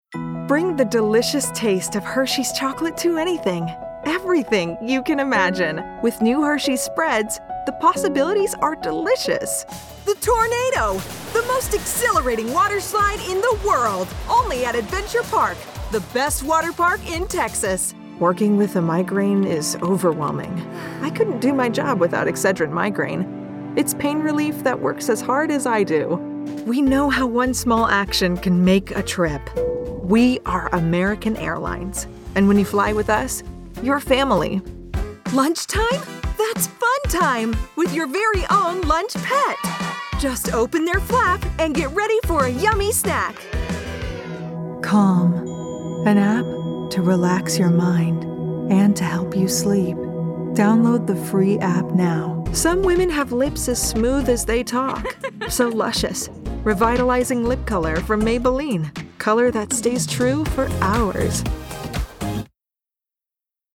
COMMERCIAL 💸
conversational
sincere
smooth/sophisticated
warm/friendly
gal/guy next door